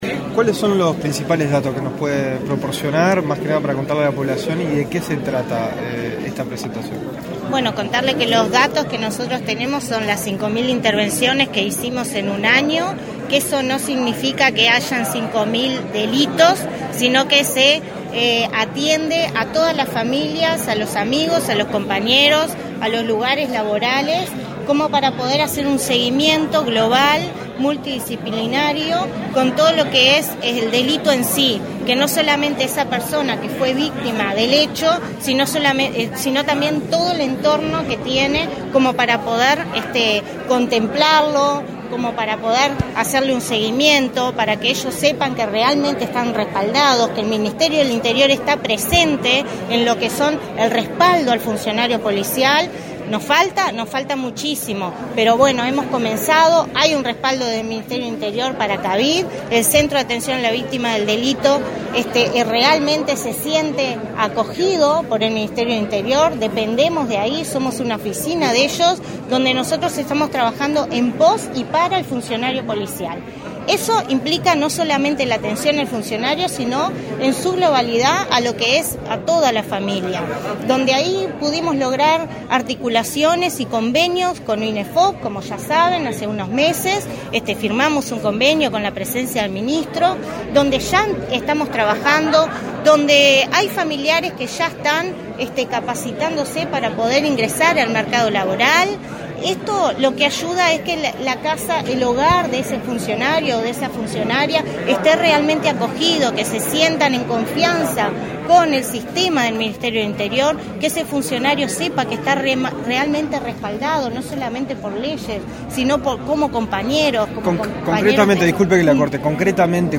Declaraciones a la prensa de la directora del Centro de Atención a las Víctimas de la Violencia y el Delito, Sandra González
Declaraciones a la prensa de la directora del Centro de Atención a las Víctimas de la Violencia y el Delito, Sandra González 06/12/2021 Compartir Facebook X Copiar enlace WhatsApp LinkedIn Tras la presentación del balance de lo actuado en los últimos dos años del Centro de Atención a las Víctimas de la Violencia y el Delito, este 6 de diciembre, la directora del Cavid efectuó declaraciones a la prensa.